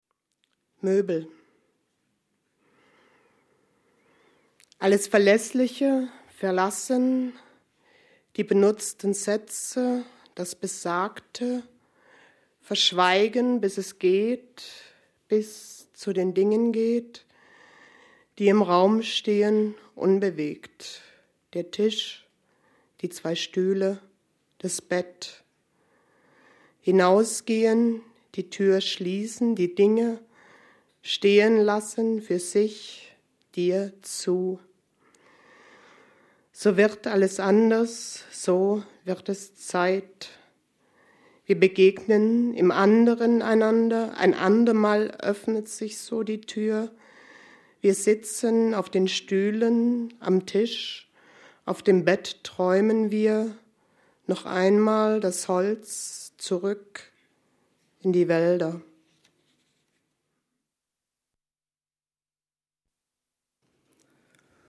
Lesung von Barbara Köhler in der literaturWERKstatt Berlin zur Sommernacht der Lyrik – Gedichte von heute